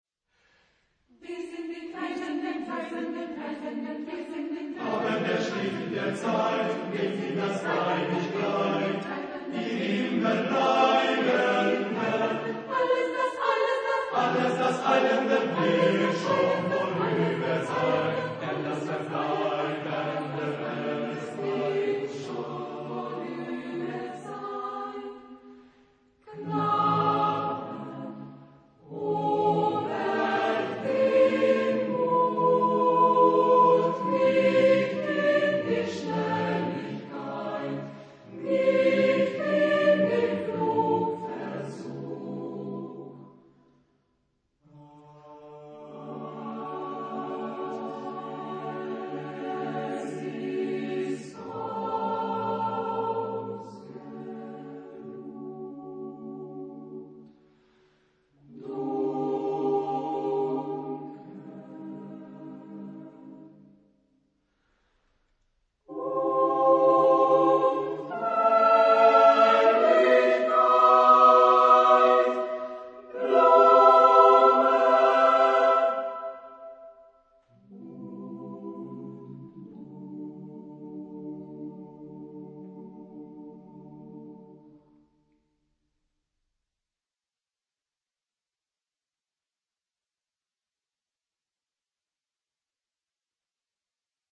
für gemischten Chor
Género/Estilo/Forma: contemporáneo ; Lied
Carácter de la pieza : rápido ; agitado
Tipo de formación coral: SATB  (4 voces Coro mixto )